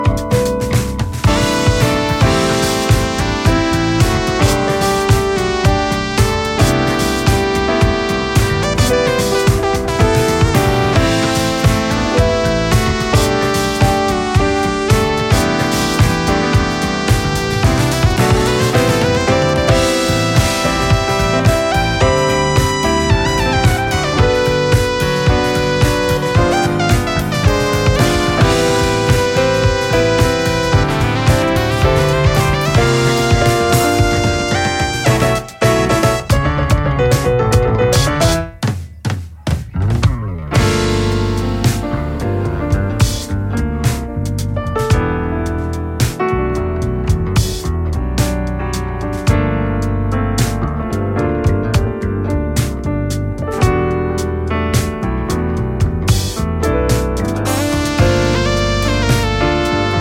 ジャンル(スタイル) JAZZ / POP JAZZ